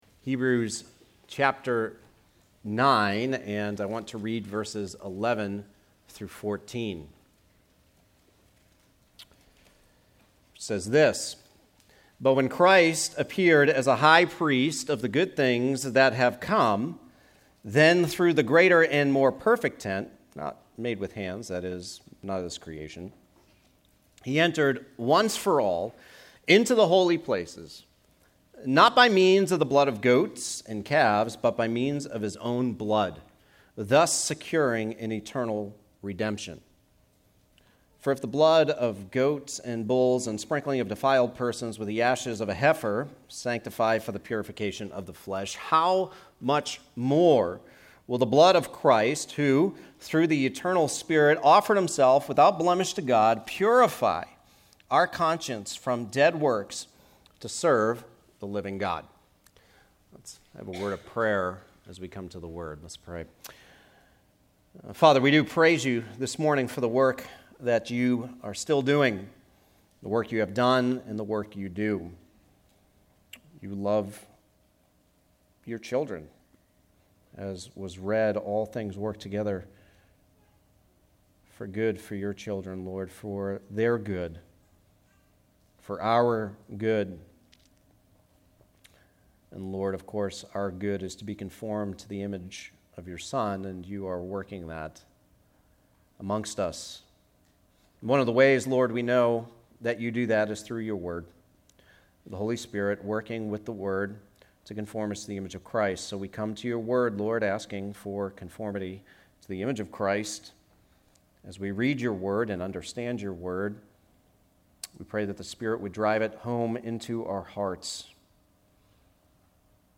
Hebrews: Jesus Is Better - Our Eternal Redemption, Part 1 Hebrews 9:11-14 Sermon 25